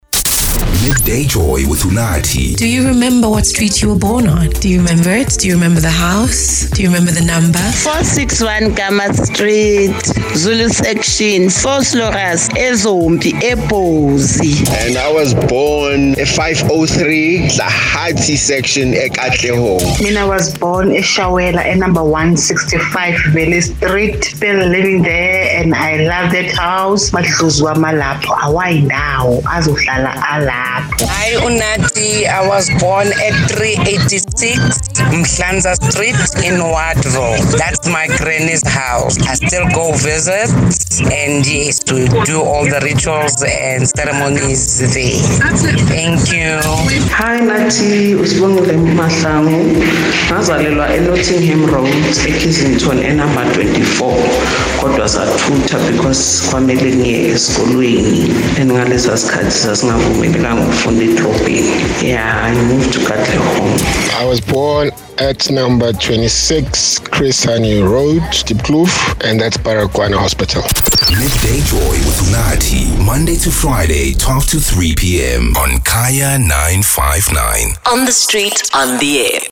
Unathi wanted to know if you remember the street you were born on. Kaya 959 listeners shared their special memories:
TOPIC-MONTAGE-WHICH-STREET-YOU-WERE-BORN-AT.mp3